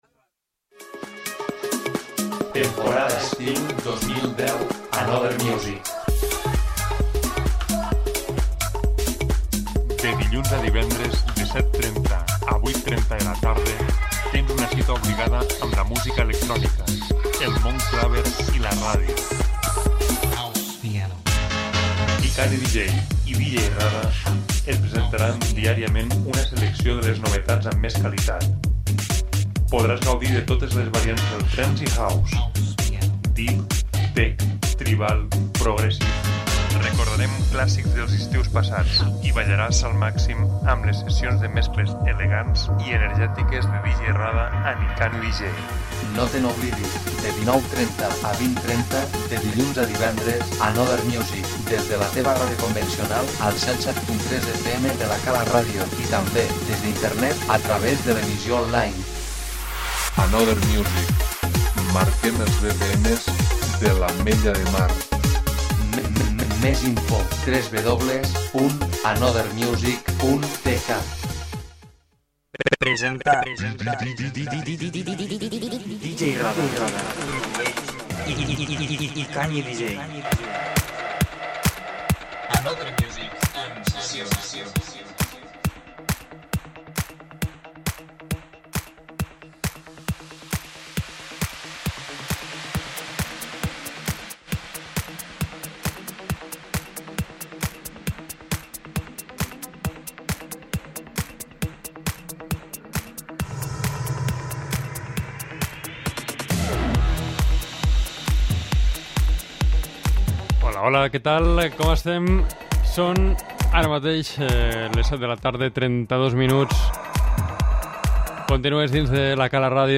punxant els últims sons electrònics de l'estiu